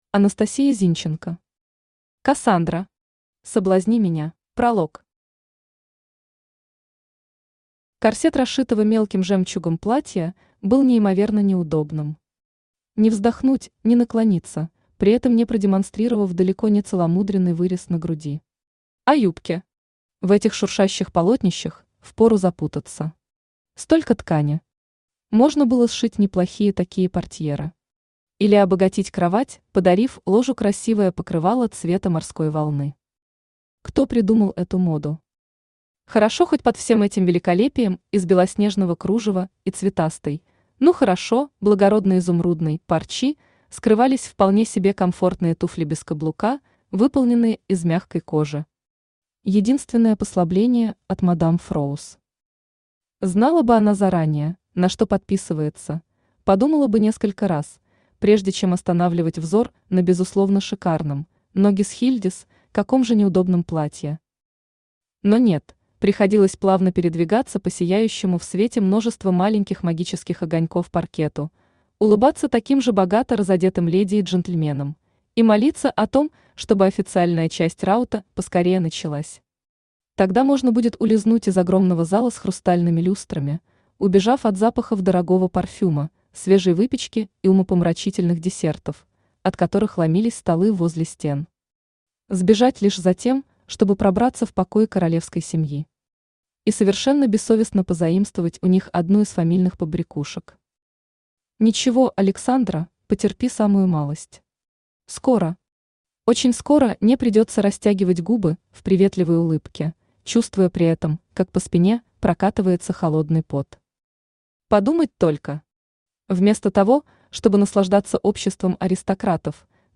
Соблазни меня Автор Анастасия Зинченко Читает аудиокнигу Авточтец ЛитРес.